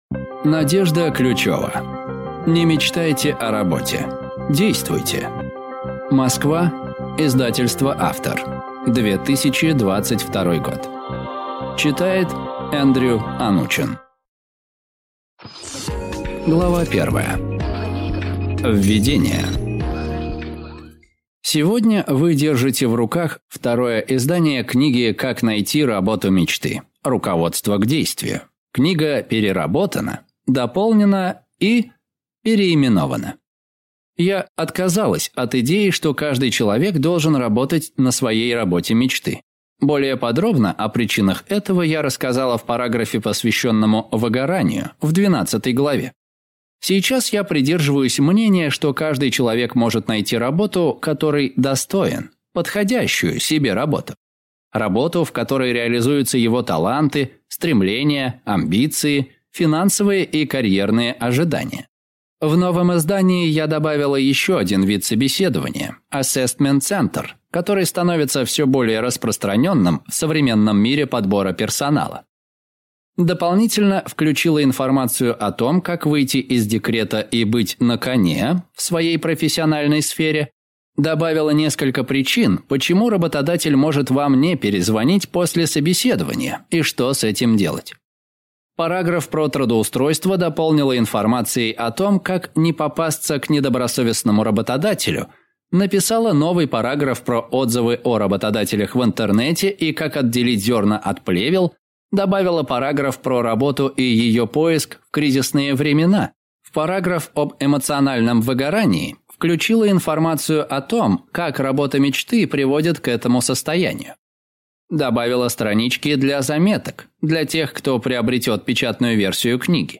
Аудиокнига Не мечтайте о работе – действуйте!
Прослушать и бесплатно скачать фрагмент аудиокниги